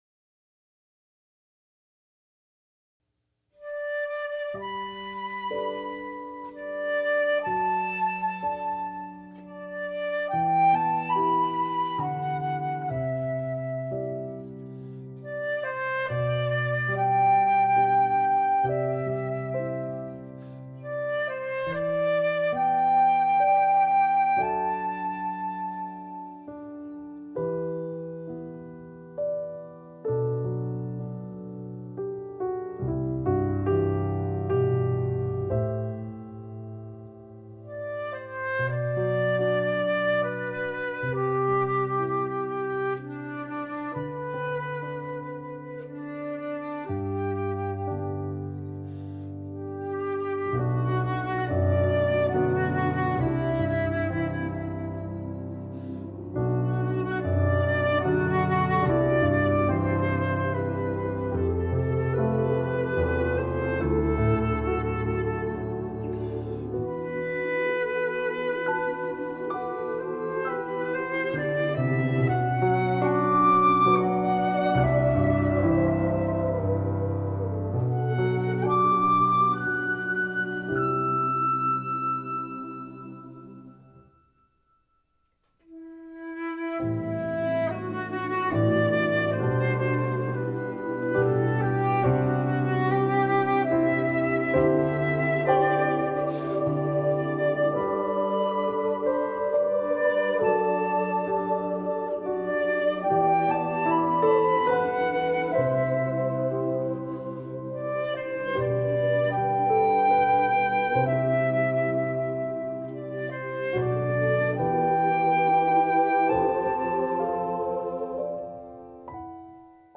Baritone, Flute, SATB Chorus, Piano, and Optional Strings.